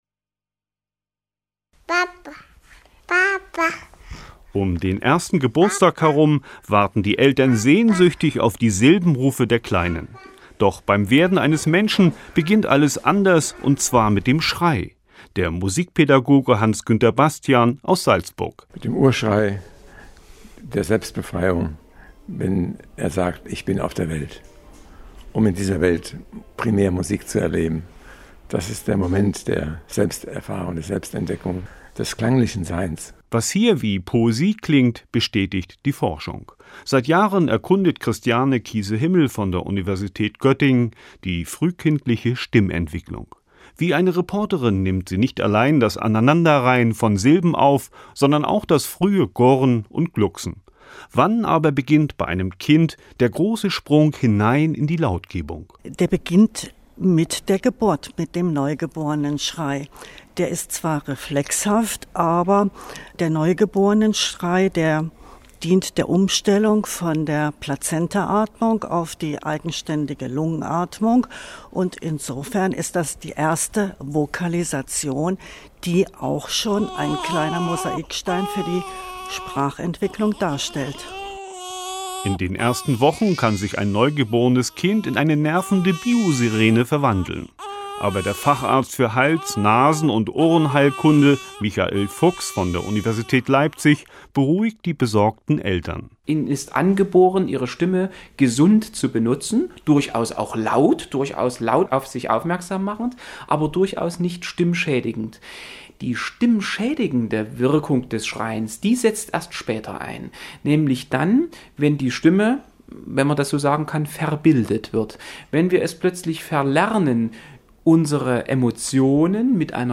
stimmentwicklung-figaro.mp3